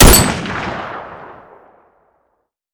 rifle.aiff